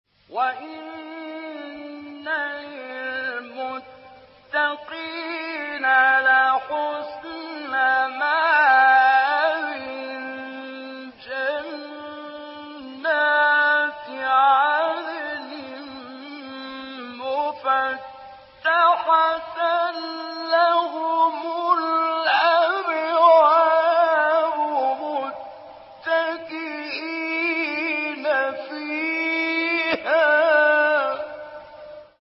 مقام چهارگاه از حمدی زامل | نغمات قرآن | دانلود تلاوت قرآن